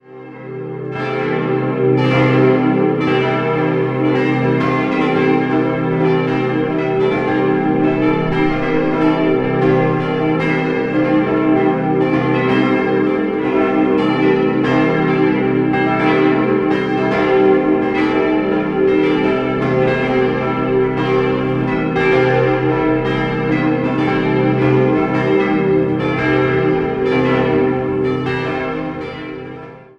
5-stimmiges Geläute: c'-e'-g'-a'-h' Die kleine Glocke wurde 1930 von Heinrich Humpert in Brilon gegossen, die drei mittleren 1948 von der Gießerei Junker in Briloner Sonderbronze und die große Glocke ergänzte 1992 die Gießerei Rincker in Sinn.